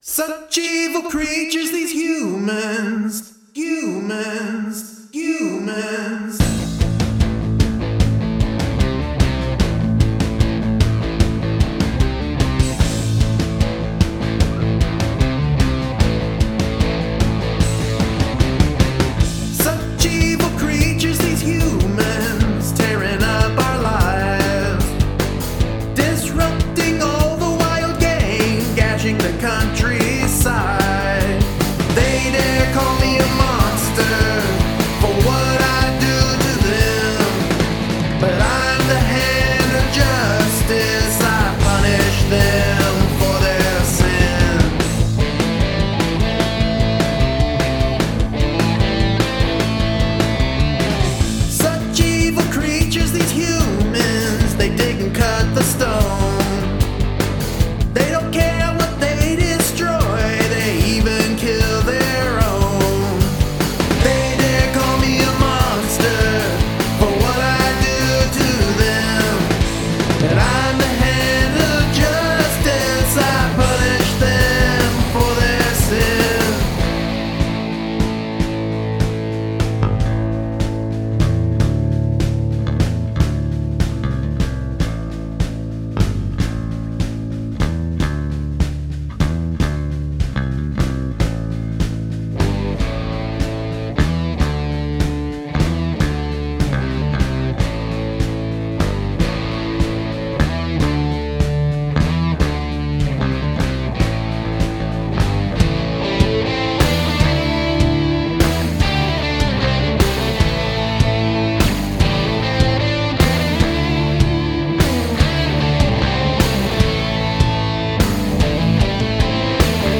Well recorded, good sounds everywhere in the recording. Bass, lead , vocals... all great.
When the guitars kick in with that rock vibe its great.
Getting a bit of a BOC vibe of this... there's a kind of horror flick / comic book feel. Great raw, dirty sound from those guitars and the bass and drums thunder along nicely too.
Oh yeah, great rocker this one.
Guitar work and bass guitar work is stellar; excellent performance all round.